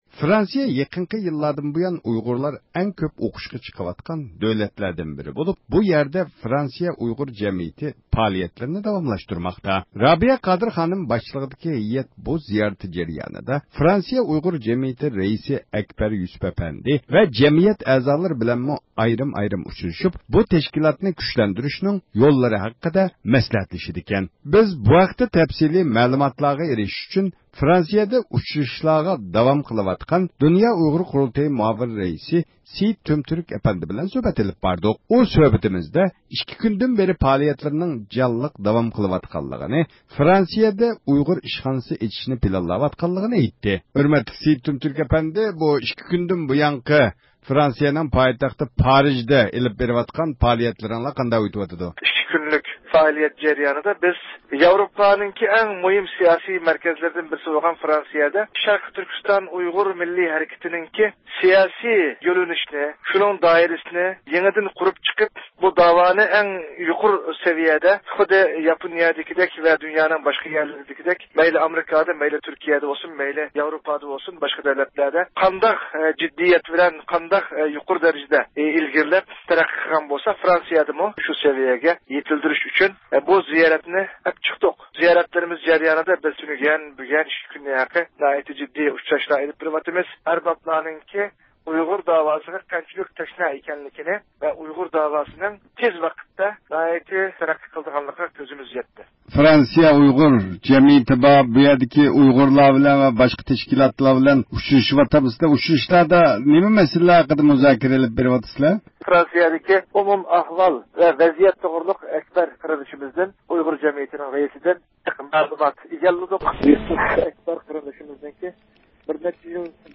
سۆھبەت